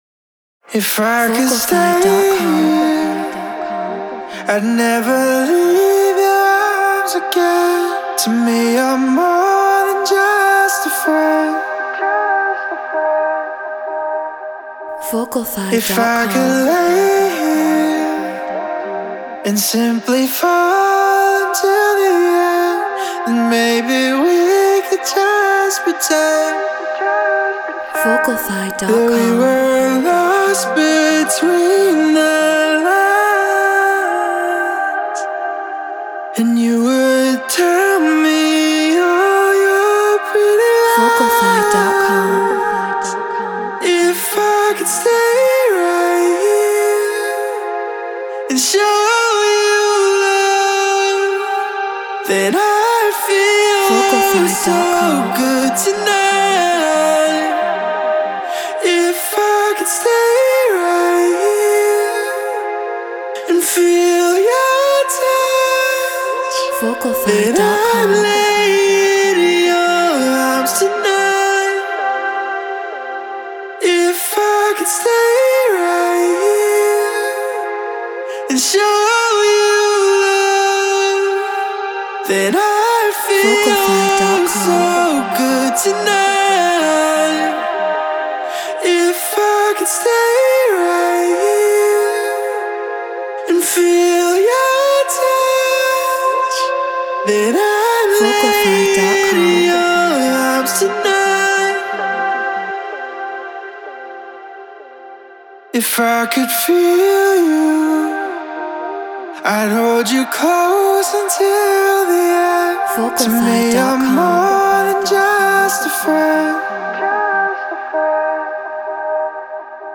House 128 BPM Fmin
RØDE NT1
Treated Room